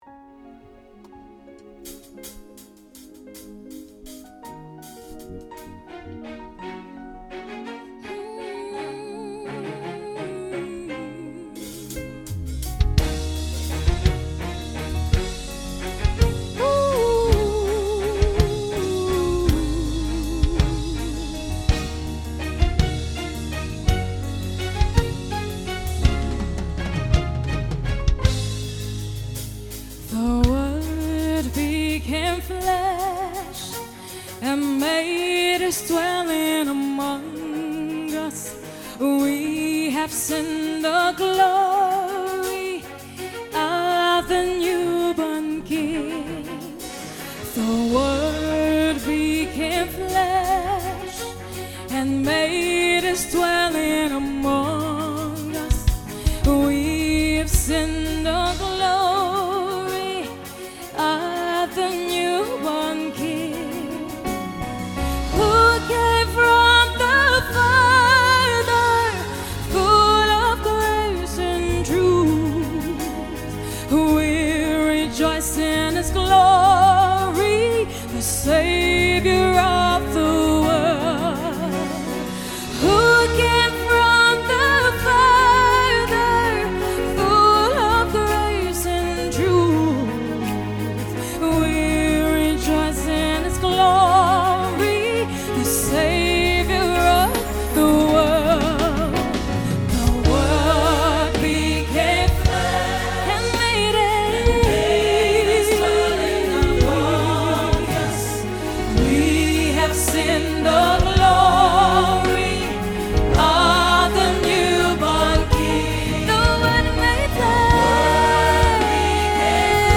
Christmas Songs